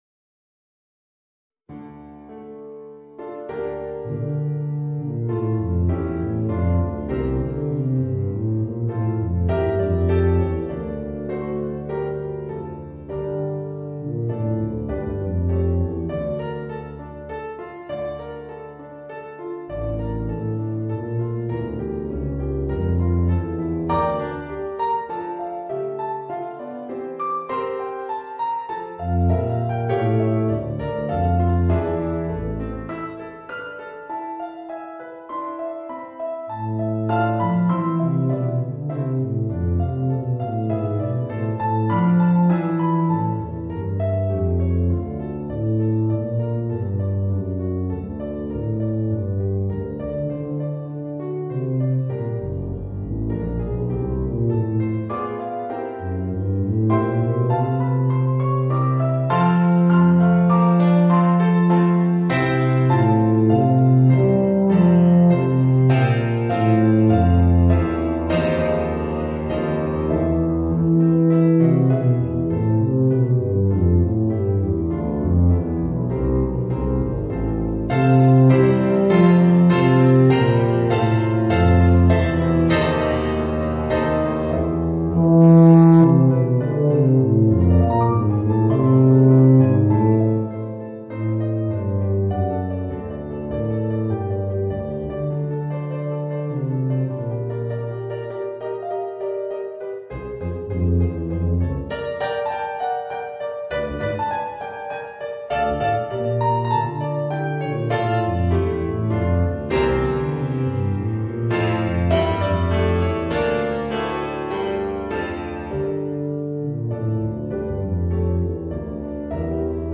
Voicing: Bb Bass and Piano